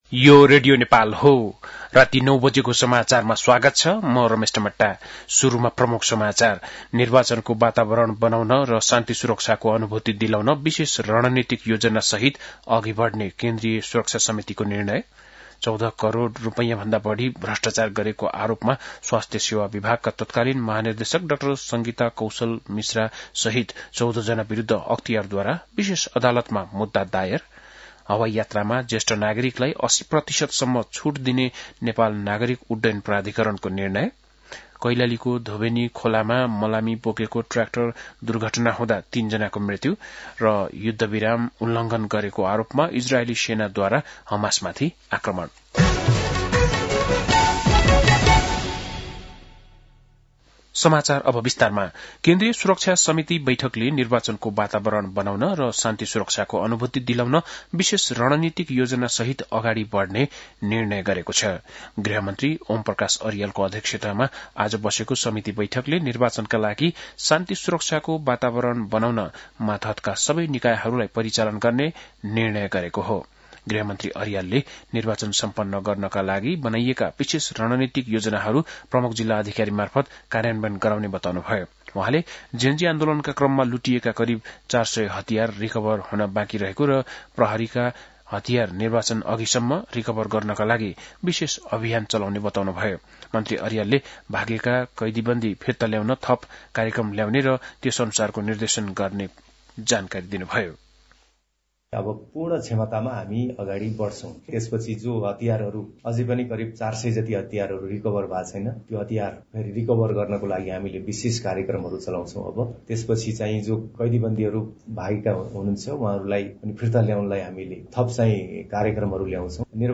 बेलुकी ९ बजेको नेपाली समाचार : २ कार्तिक , २०८२
9-pm-nepali-news-.mp3